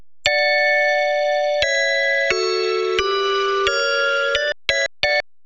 その他素材(Bell Pad)試聴